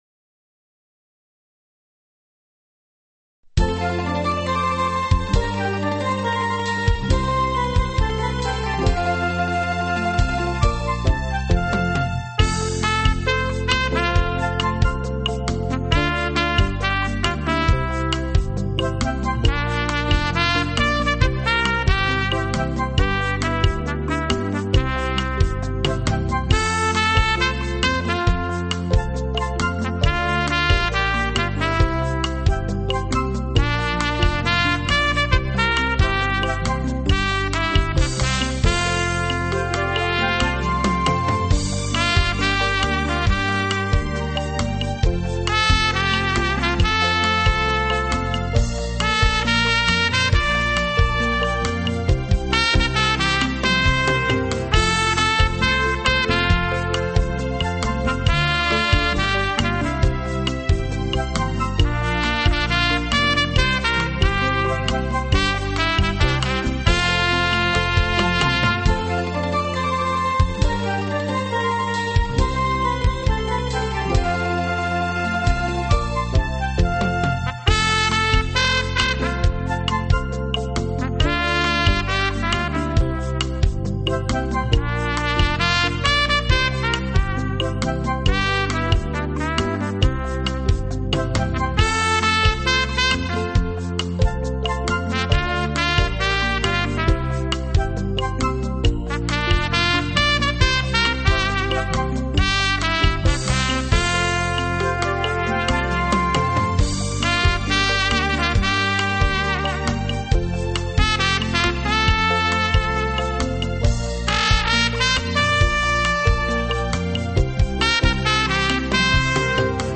清脆、嘹亮；高亢、辉煌; 温柔、抒情；优美的小号音乐
(小号独奏)
小号的声音清脆、嘹亮、高亢、辉煌，同时也能吹得温柔、抒情、优美。